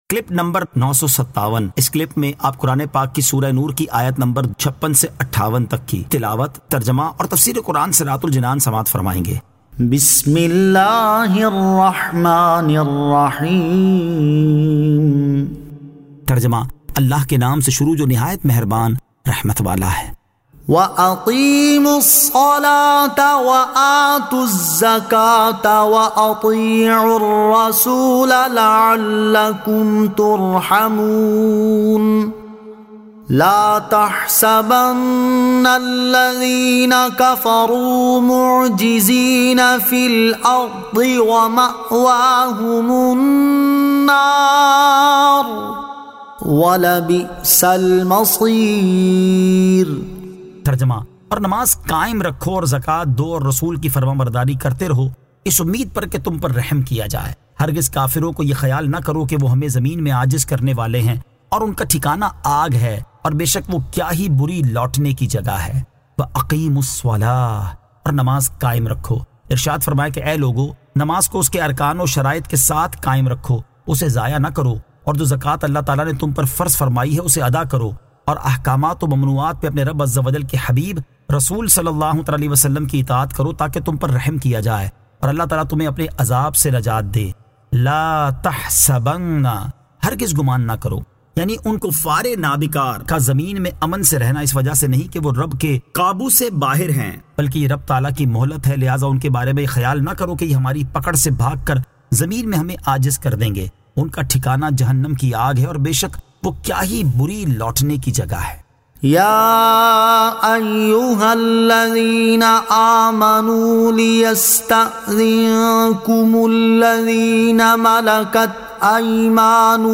Surah An-Nur 56 To 58 Tilawat , Tarjama , Tafseer